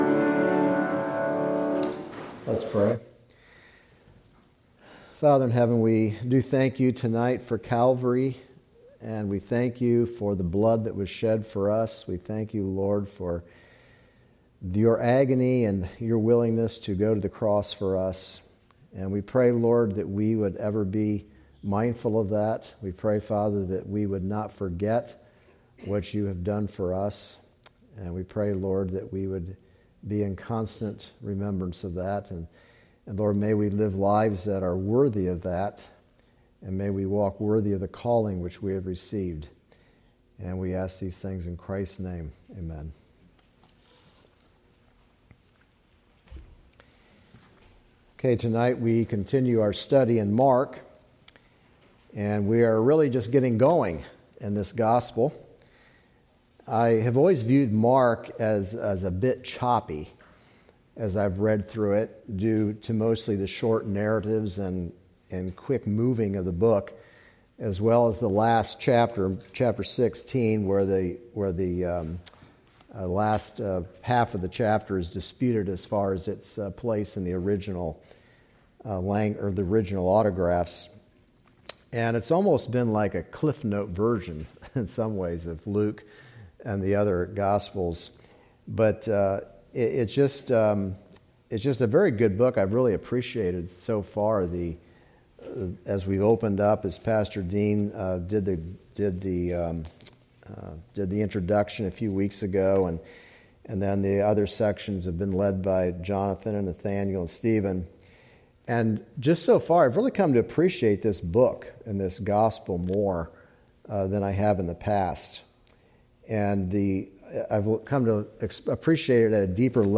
Mark 1 Service Type: Wednesday Devotional « David’s Choice of Punishment